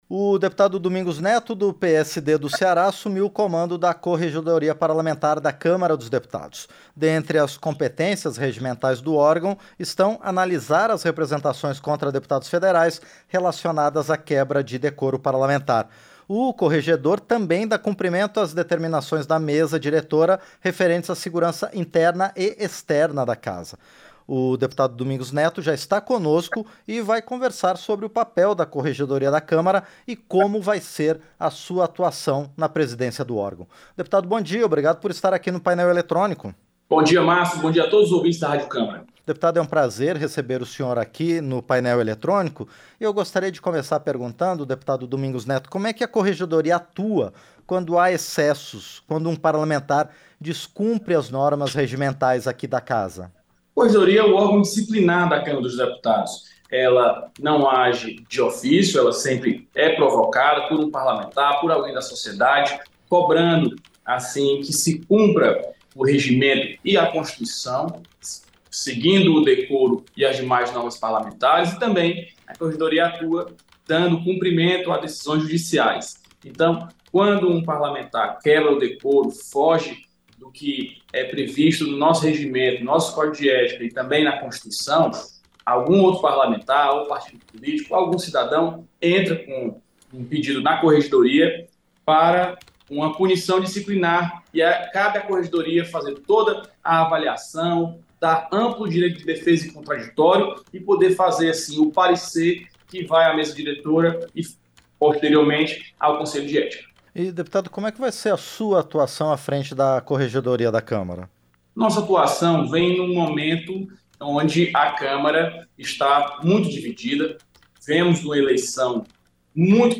• Entrevista - Dep. Domingos Neto (PT-RS)
Programa ao vivo com reportagens, entrevistas sobre temas relacionados à Câmara dos Deputados, e o que vai ser destaque durante a semana.